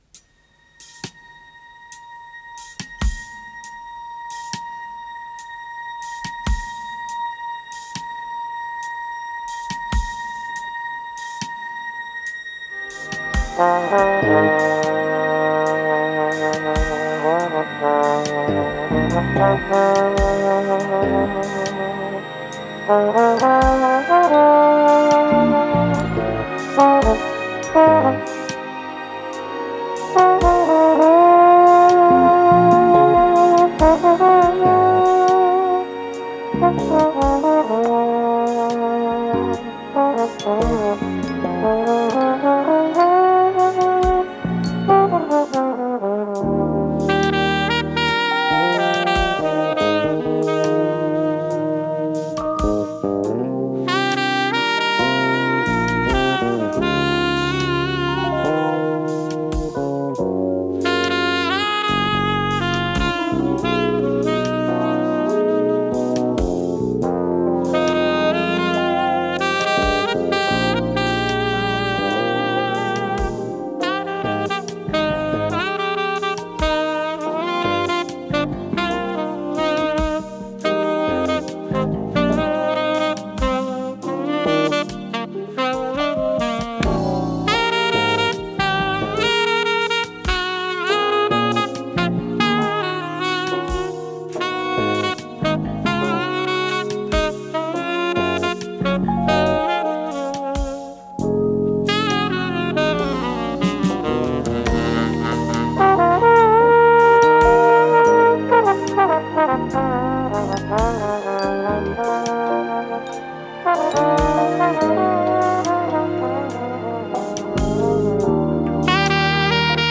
с профессиональной магнитной ленты
саксофон-сопрано
ВариантДубль моно